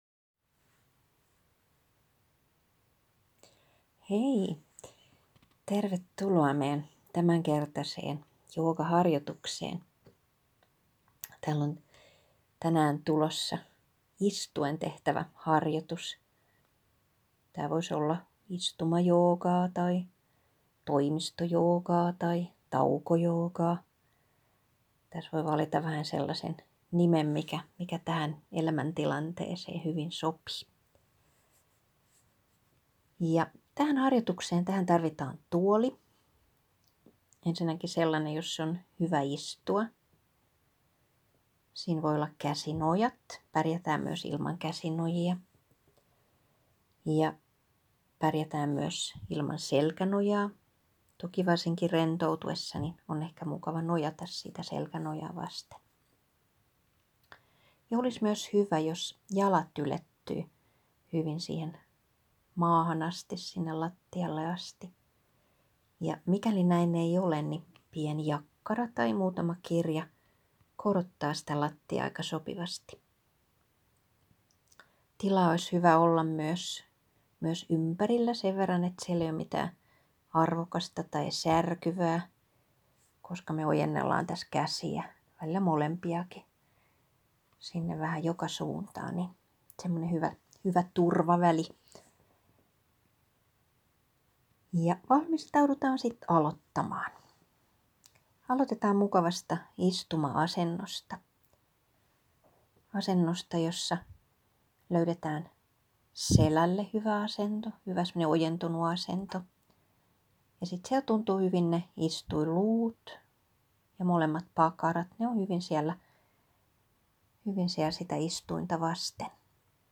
Istumajoogaa.m4a